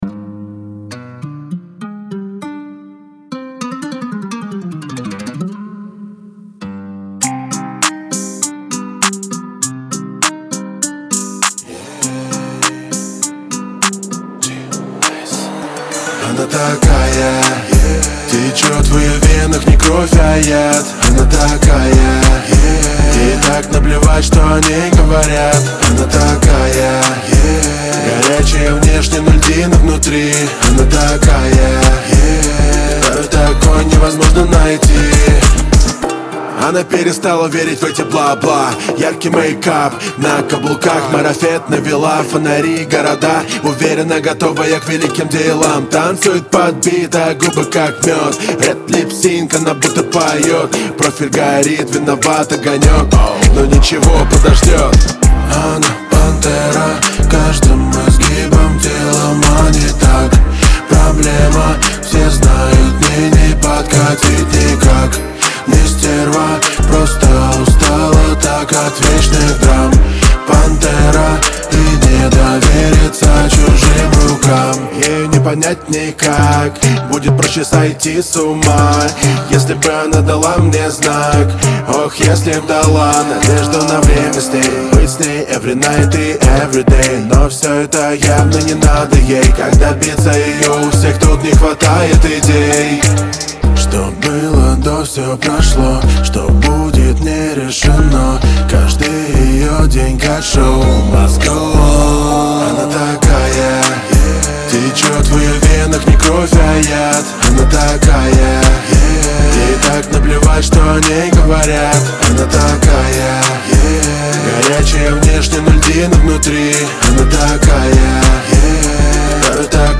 грусть
Шансон